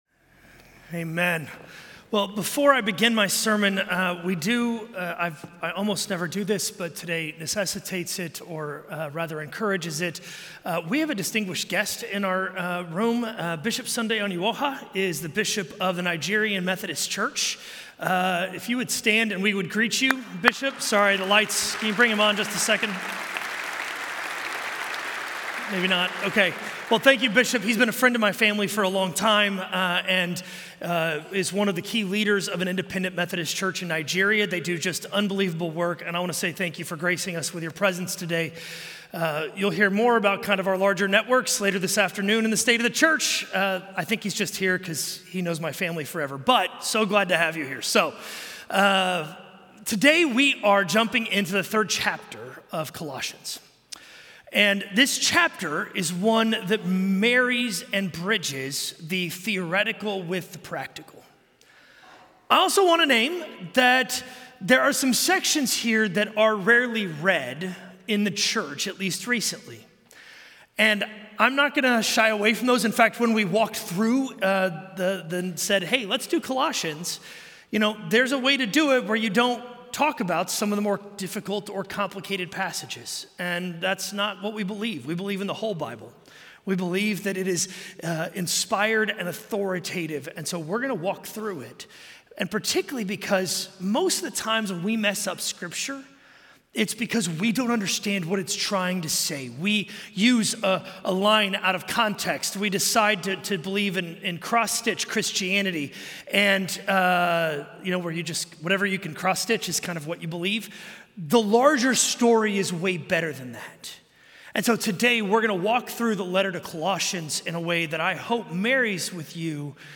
A message from the series "Prophets."